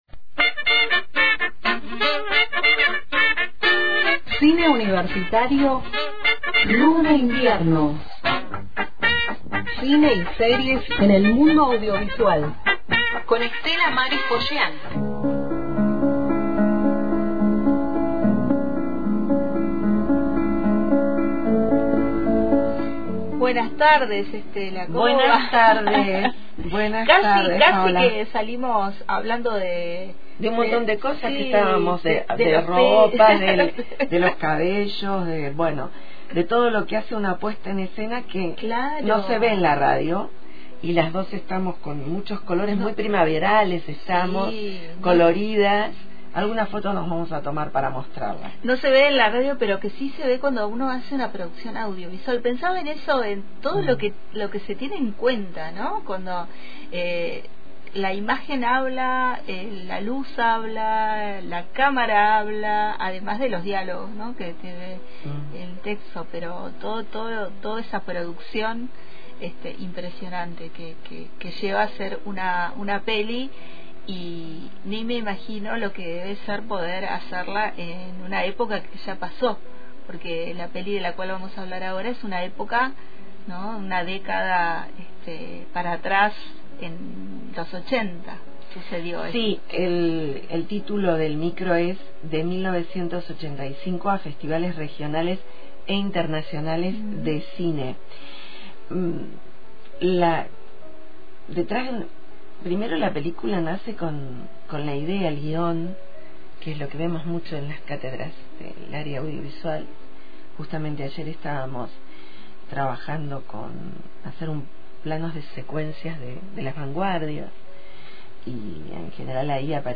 Sobre la reciente estrenada película, escuchemos testimonios de sus actores en la presentación en el Festival de Venecia y palabras de su director, Santiago Mitre.